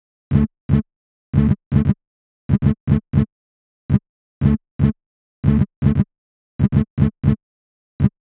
• 117 bpm synth envelope sequence.wav
117_bpm_synth_envelope_sequence_8QO.wav